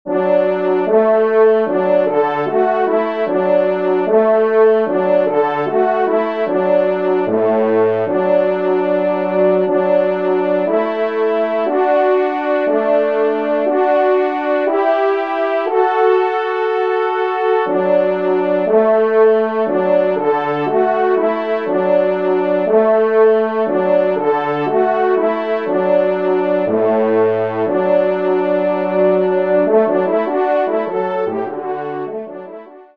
Genre :  Divertissement pour Trompe ou Cor
ENSEMBLE